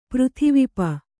♪ přrhivi pa